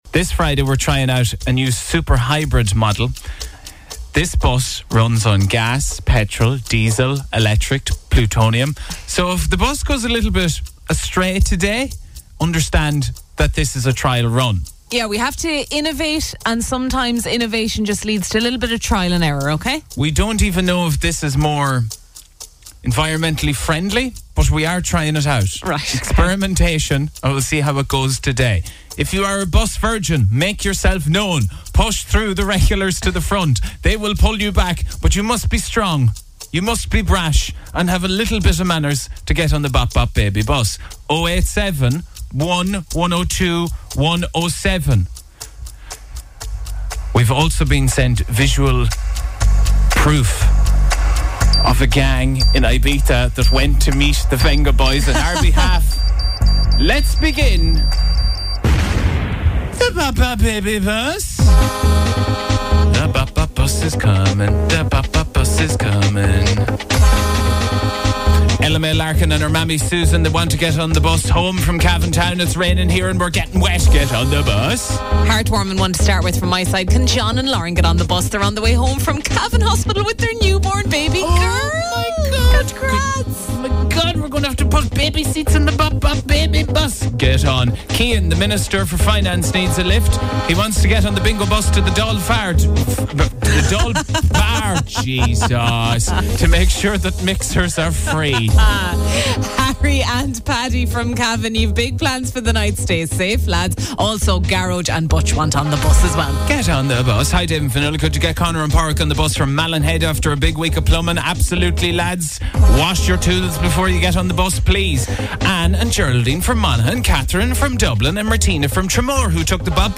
MACKLEMORE - Interview - 31.08.2022